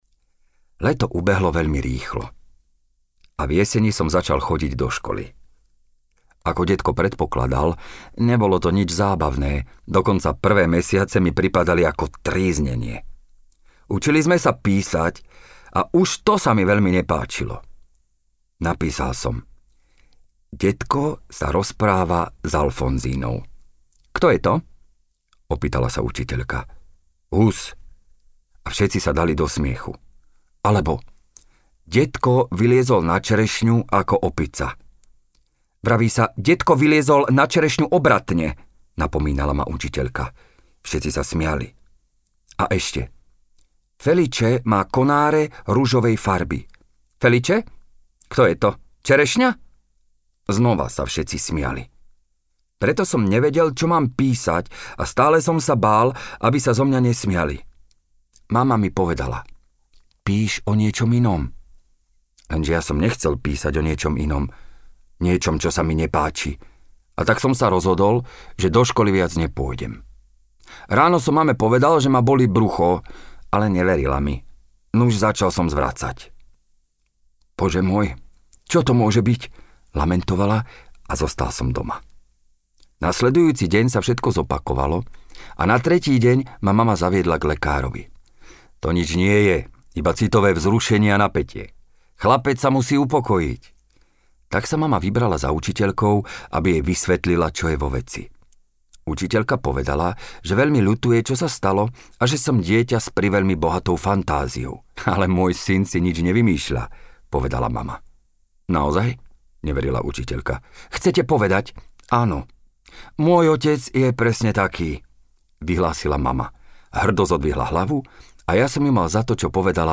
Audiokniha: Môj dedko je čerešňa
Audiokniha pre deti Od 7 rokov
Čita: Ľuboš Kostelný
nahrávka a mix BMT studio 2026